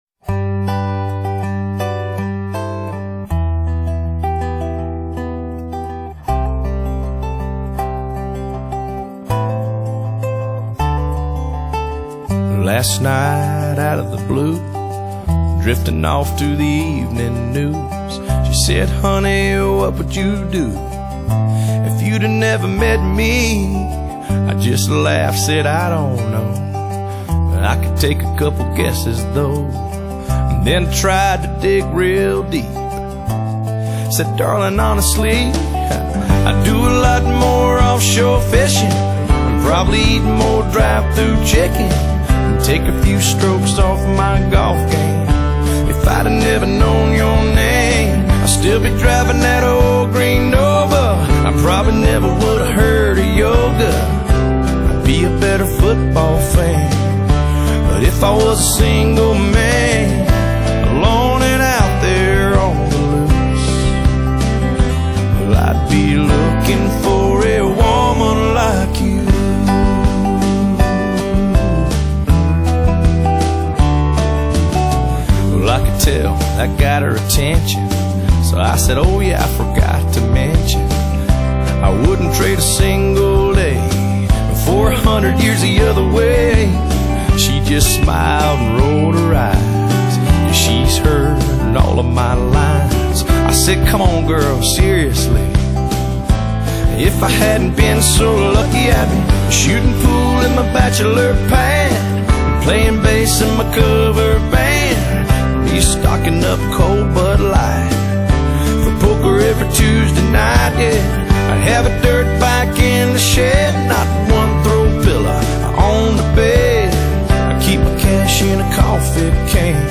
【鄉村歌曲】